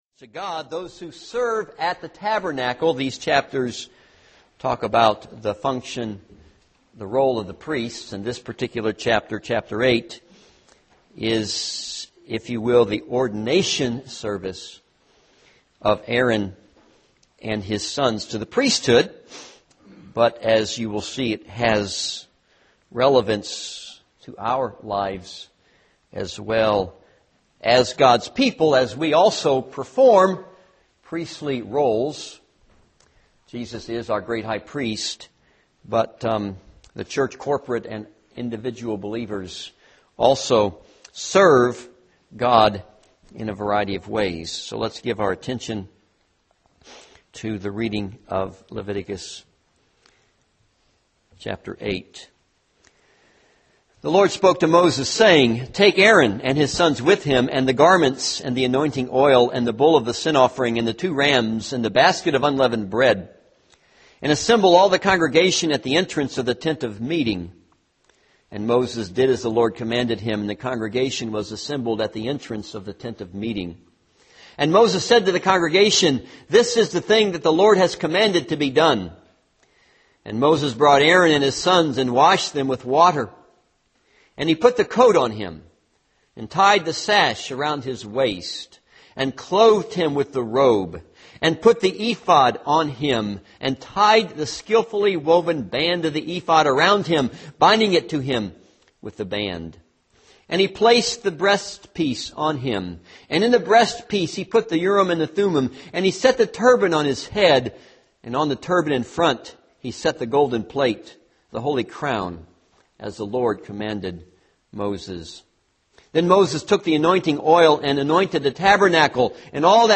This is a sermon on Leviticus 8.